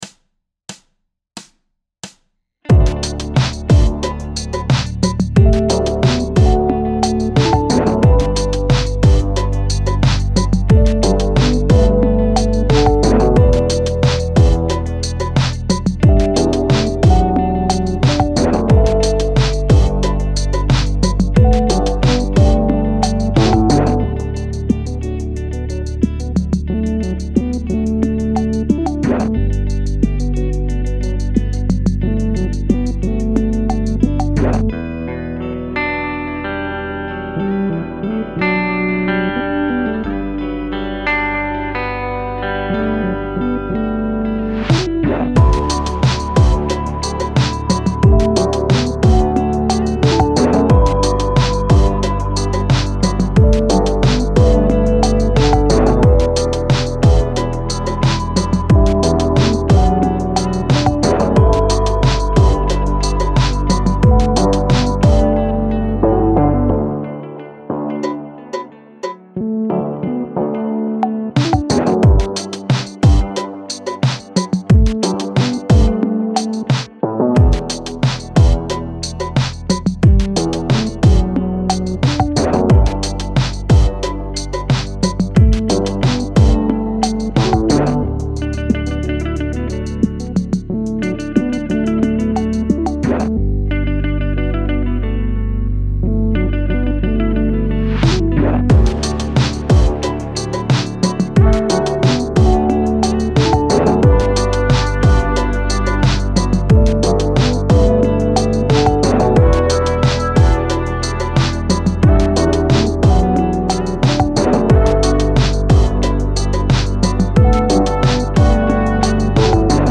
Performance Backing Track (No Guitar)